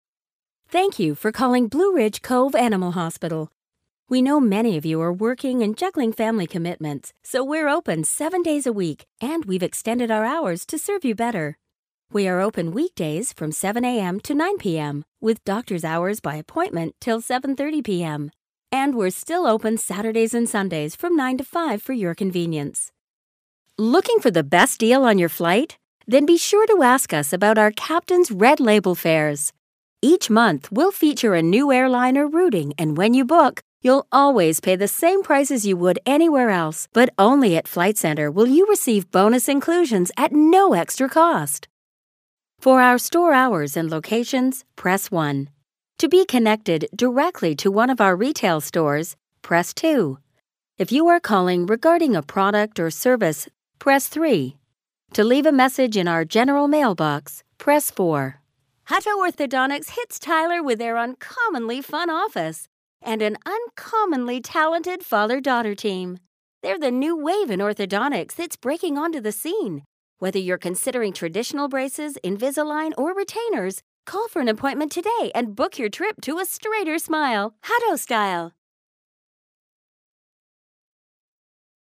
Female
Bright, Confident, Corporate, Friendly, Natural, Posh, Reassuring, Smooth, Soft, Warm, Versatile, Young, Approachable, Authoritative, Conversational, Energetic, Engaging, Upbeat
Canadian (native) neutral North American British RP
Audio equipment: professionally built booth / UR22mkII interface